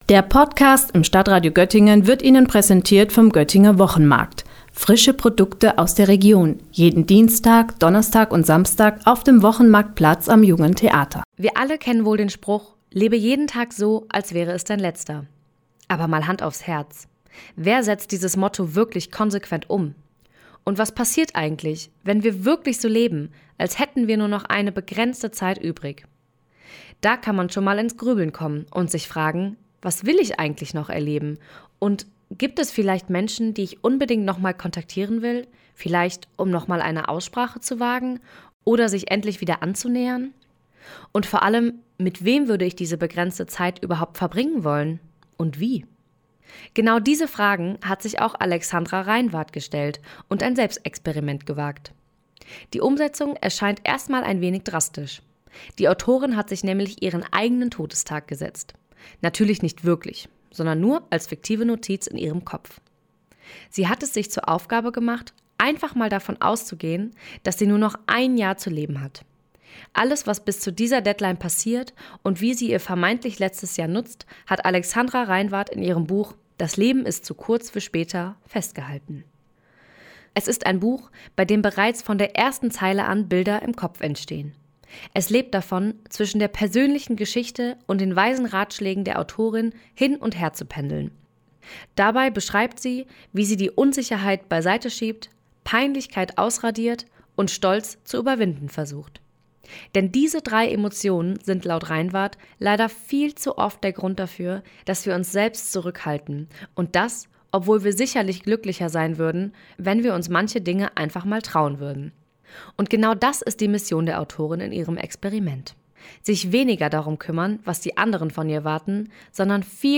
Beiträge > „Das Leben ist zu kurz für später“ – Rezension zu einem Gedanken-Experiment - StadtRadio Göttingen